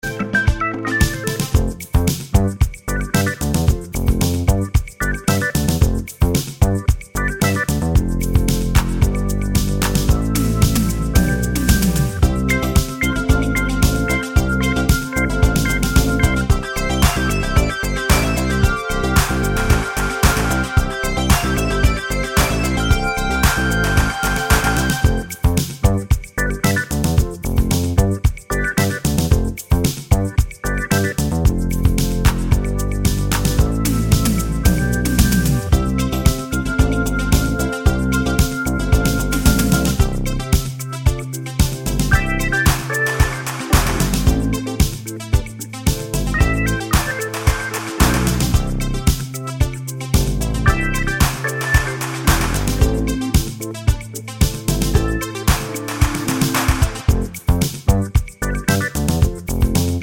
no Backing Vocals Pop (1980s) 3:54 Buy £1.50